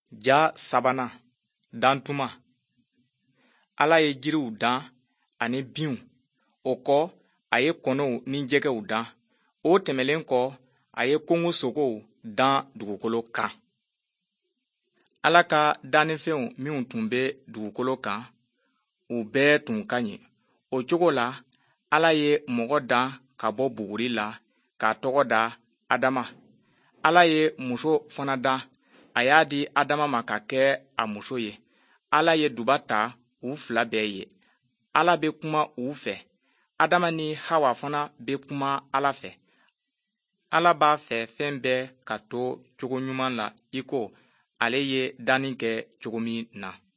Especially with the voiced velar fricative I hear.
The recording comes from the GRN (no. 3)
Stumped by the rate of speech.